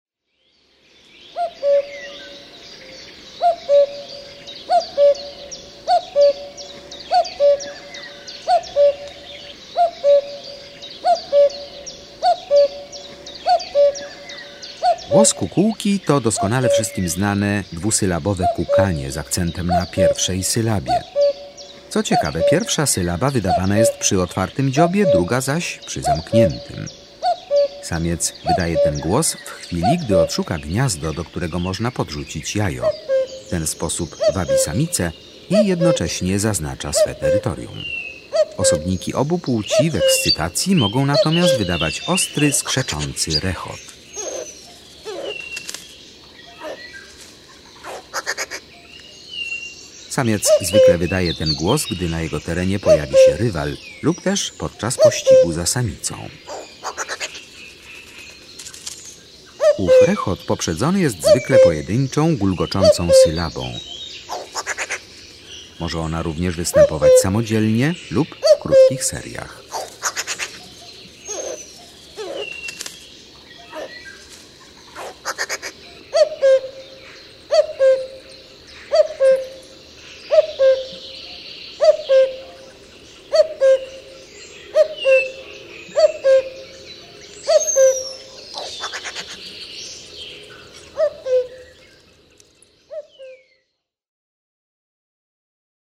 23 Kukułka.mp3